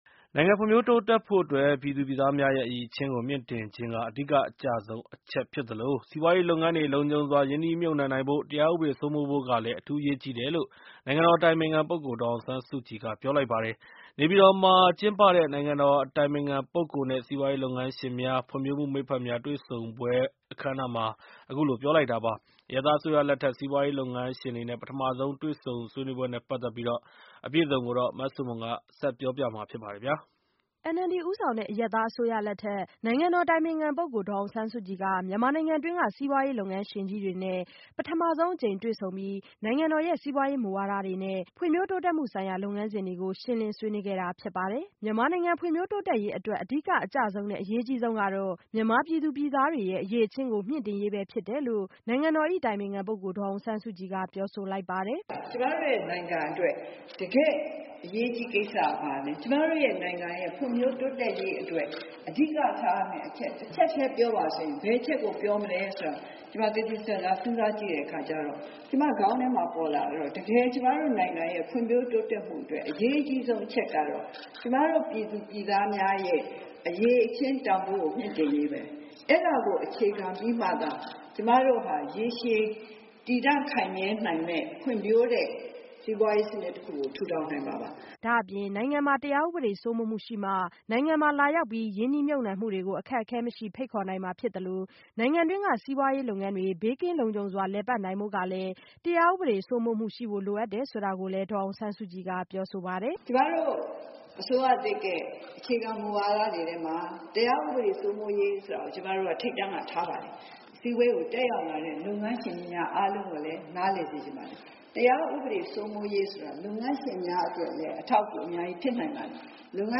ဒေါ်အောင်ဆန်းစုကြည် စီးပွားရေးလုပ်ငန်း ရှင်များနဲ့ တွေ့ဆုံပွဲမှာ မိန့်ခွန်းပြောကြား